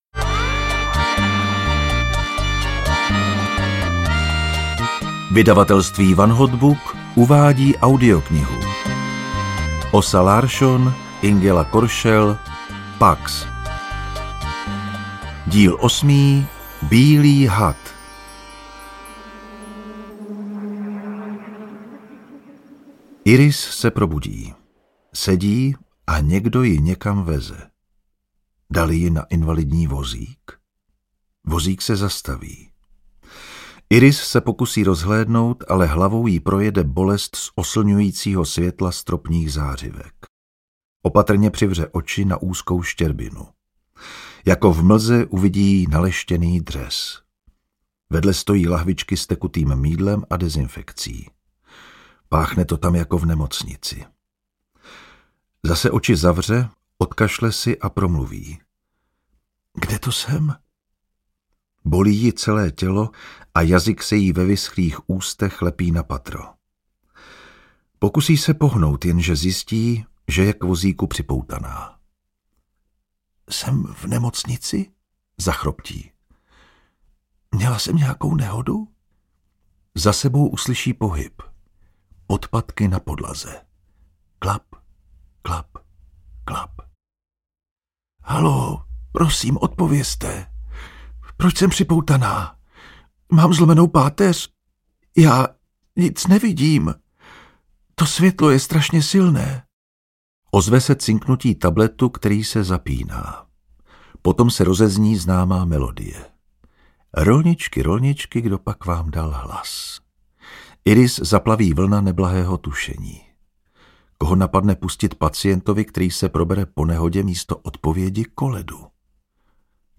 PAX 8: Bílý had audiokniha
Ukázka z knihy
• InterpretJan Vondráček